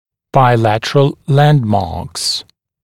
[baɪ’lætərəl ‘lændmɑːks][бай’лэтэрэл ‘лэндма:кс]цефалометрические ориентиры, имеющиеся с обеих сторон